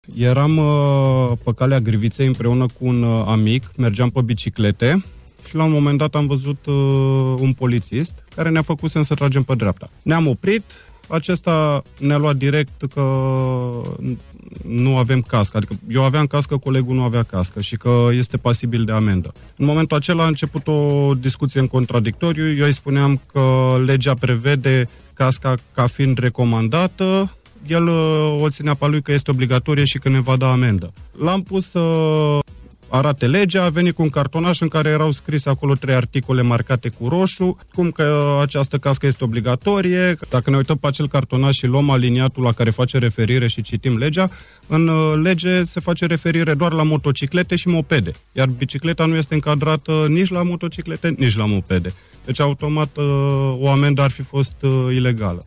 Asta dupa ce cazul a fost mediatizat astazi la Bucuresti fm in Pauza de Pranz.